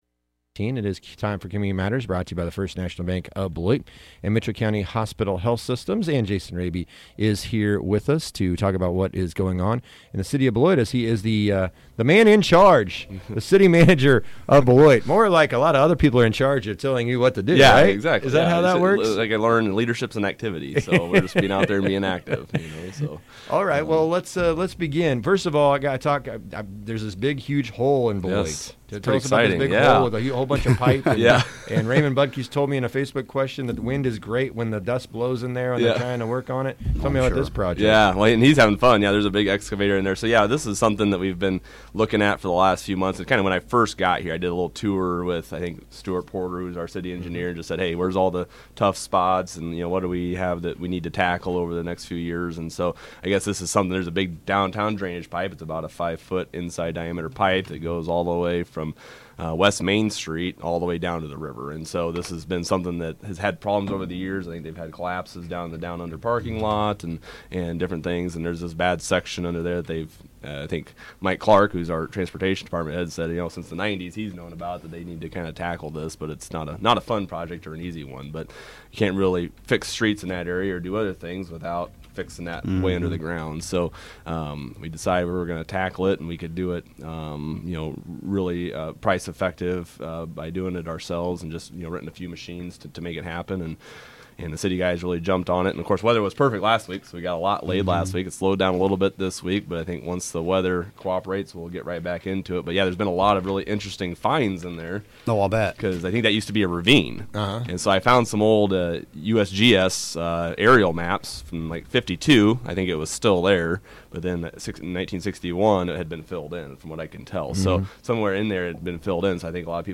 The City Manager of Beloit updates us on current city projects and other projects that may be on the horizon.